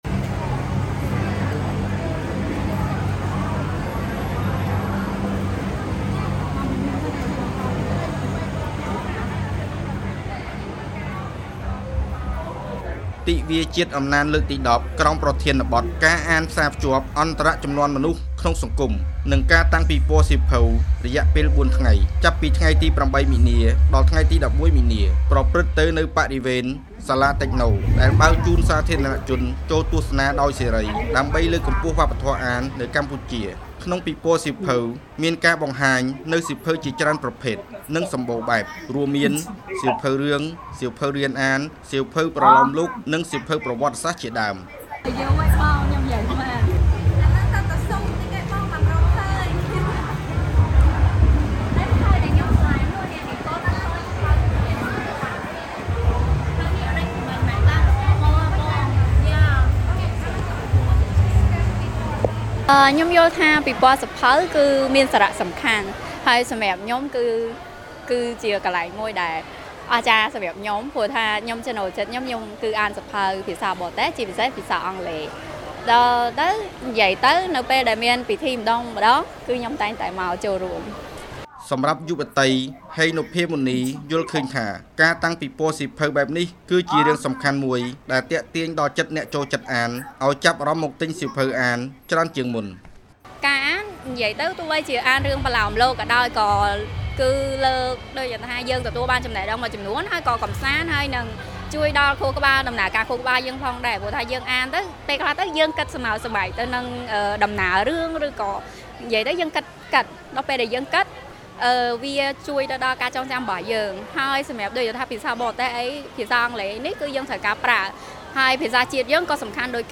បទយកការណ៍